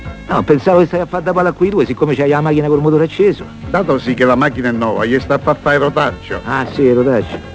E devi vedere come ci restano quando il flat six da 245 cavallini li spernacchia passandoli col calcolato cambio di marcia.!!!!